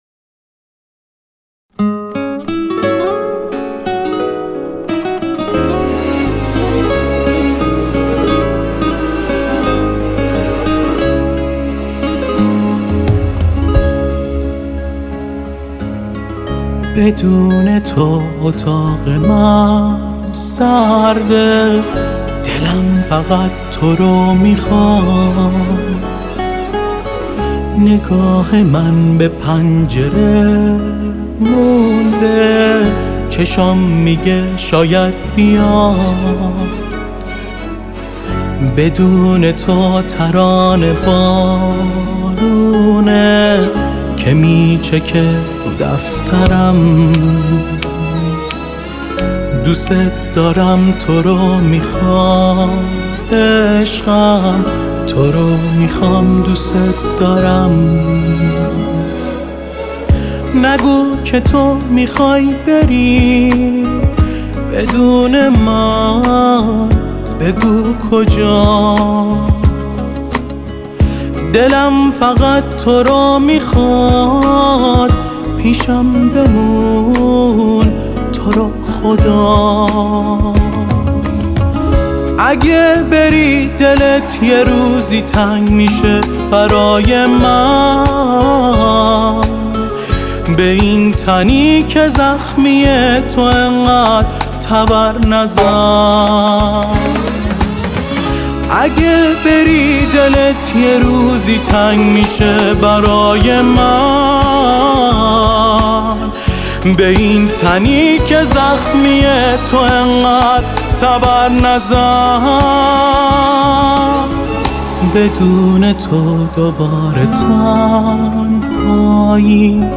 ویلن
گيتار